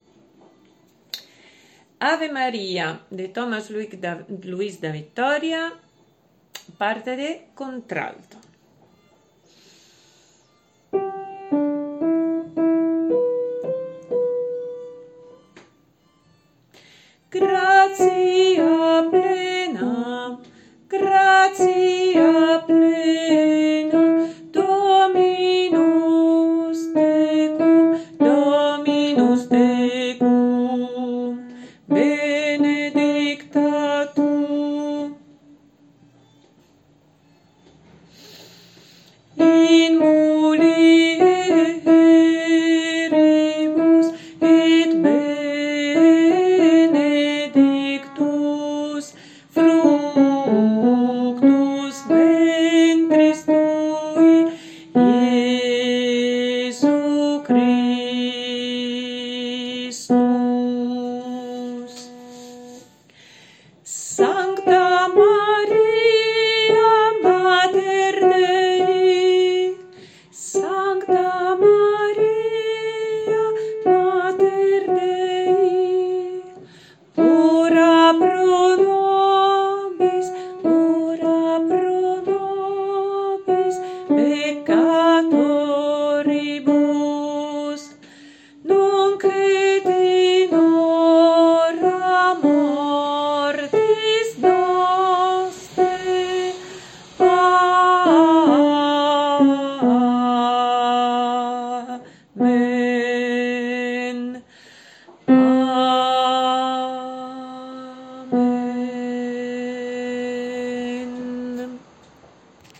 CONTRALTO